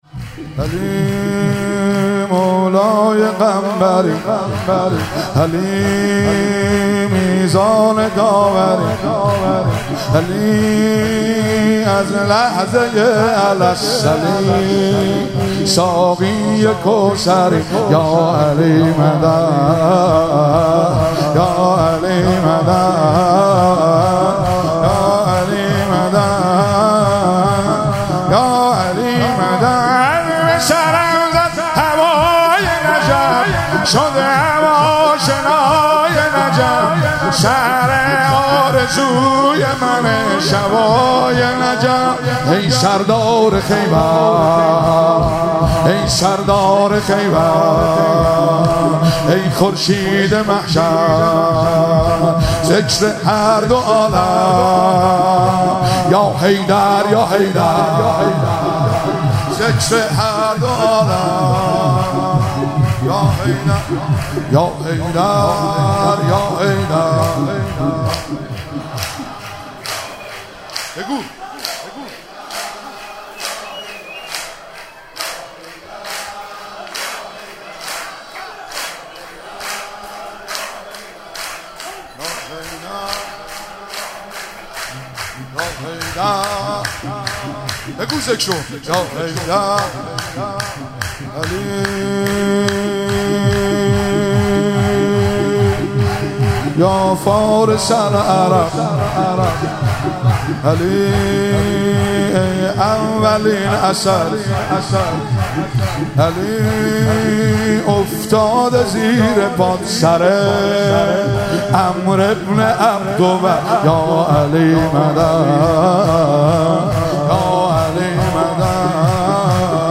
مراسم جشن سالگرد ازدواج حضرت امیرالمومنین علی علیه السلام و حضرت فاطمه زهرا سلام الله علیها- خرداد 1402
شور- علی مولای قنبری علی میزان داوری